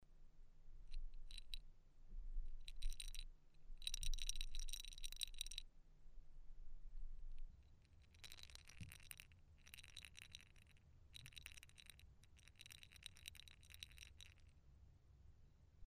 Sound recordings of the original pellet bells and bells from the Avar period kept in the collection of the Great Migration Period of the Hungarian national Museum Budapest.
Sound of original pellet bell Halimba-Belátó-domb grave 81 0.24 MB
Pellet_bell_Halimba_belato_domb_grave_81.MP3